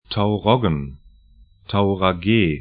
Pronunciation
Tauroggen tau'rɔgən Tauragė taura'ge: lt Stadt / town 55°15'N, 22°17'E